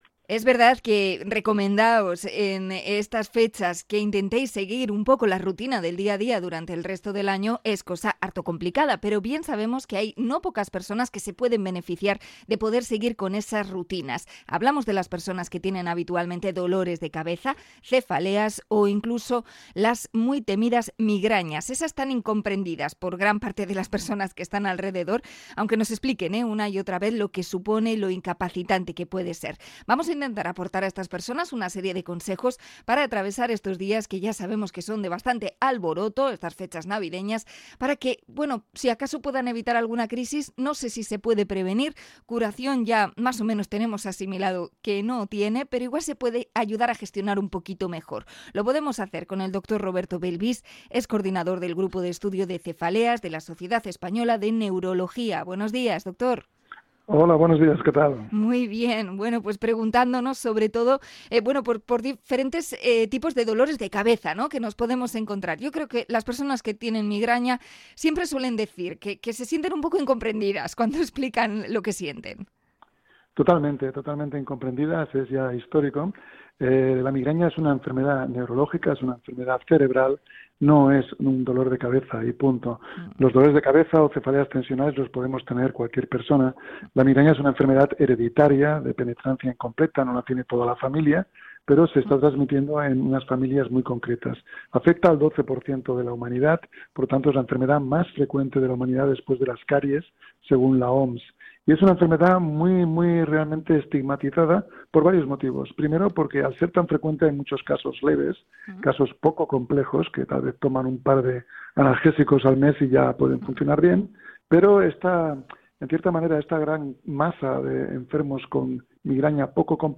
Entrevista con neurólogo por las fiestas de Navidad